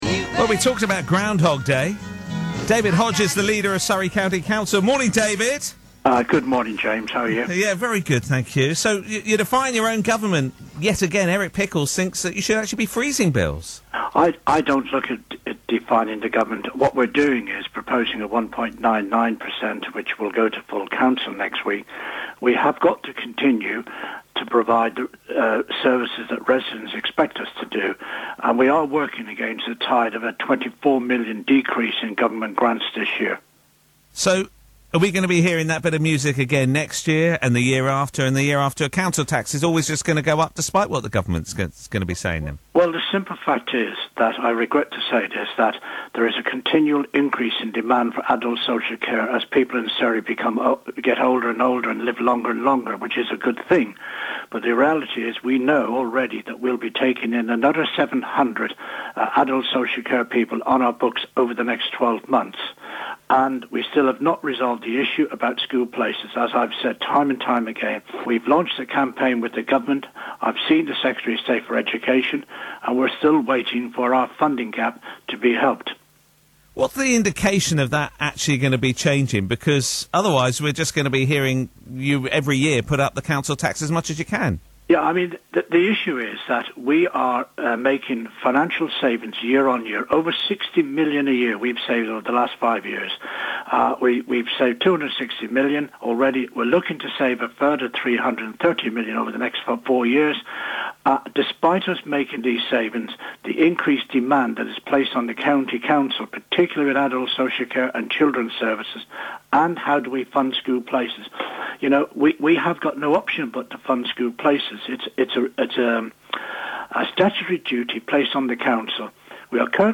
Leader David Hodge was today interviewed